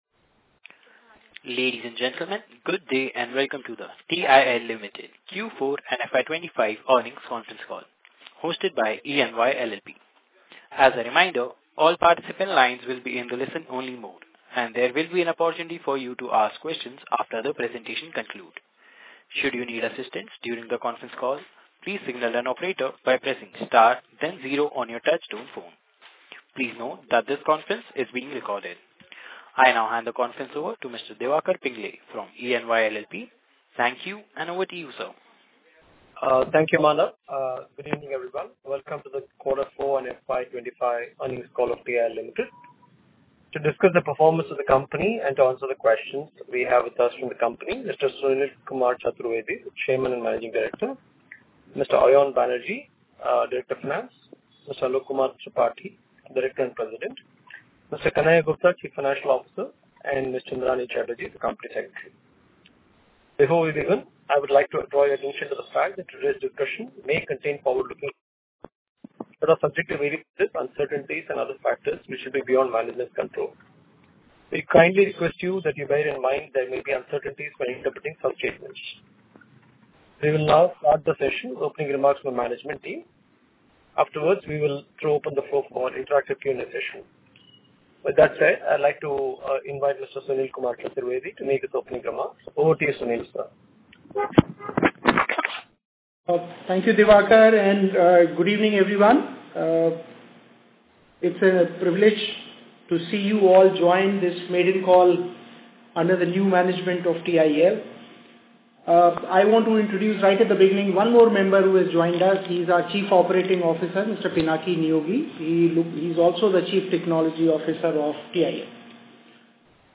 TIL Ltd Q4FY25 Earnings Concall on 26th May 2025